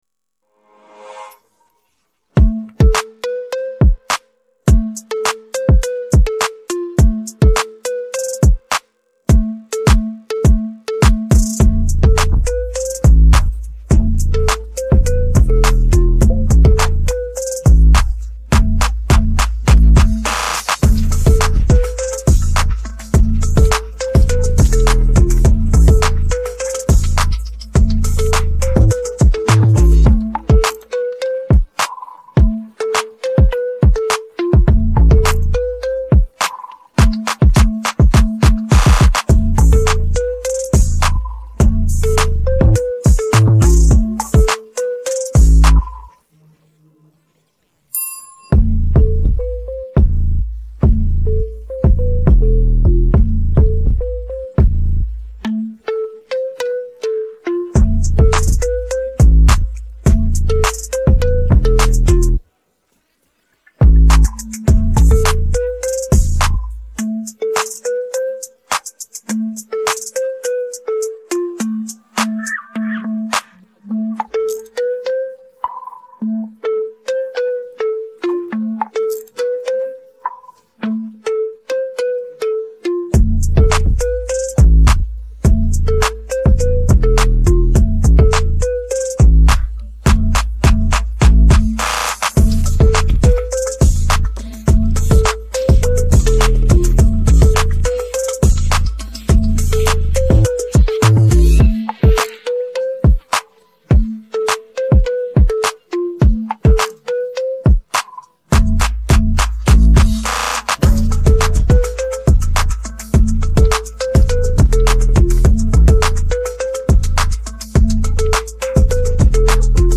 Background Music